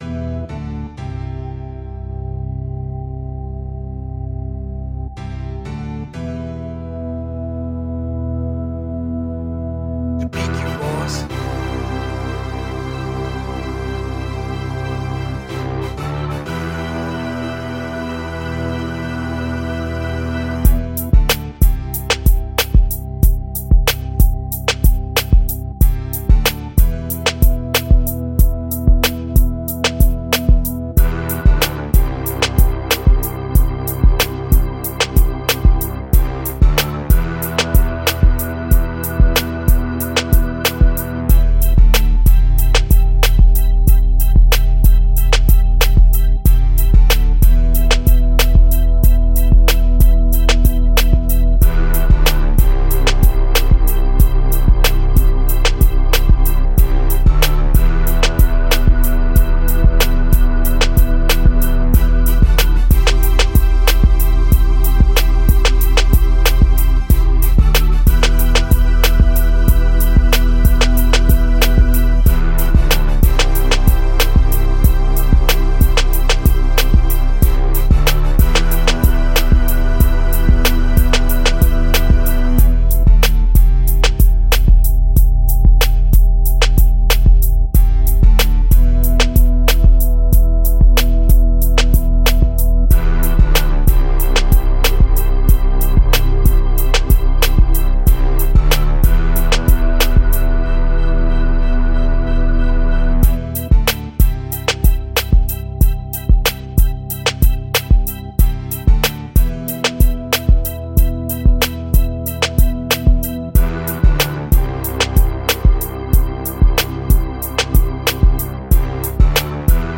Old school feel with aggressive chords and drums.